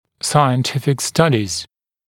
[ˌsaɪən’tɪfɪk ‘stʌdɪz][ˌсайэн’тифик ‘стадиз]научные исследования